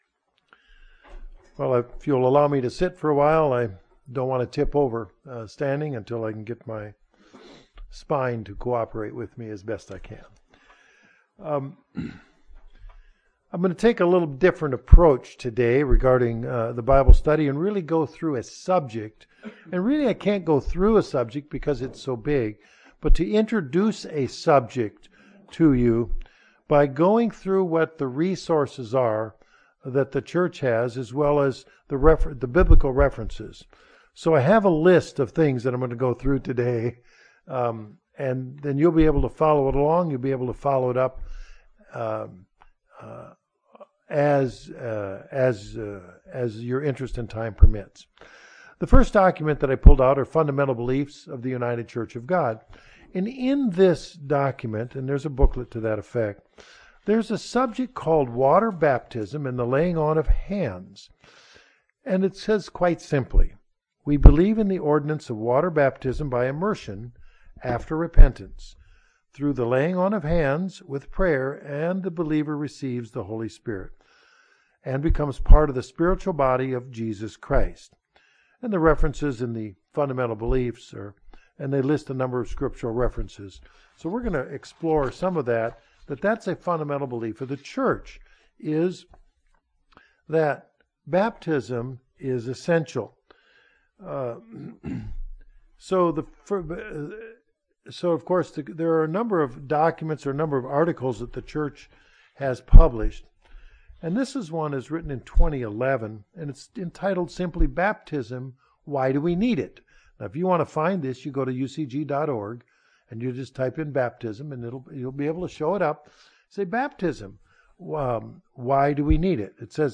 In this Bible Study, church reference materials are provided during this introduction to the process of becoming baptized.
Given in Northwest Arkansas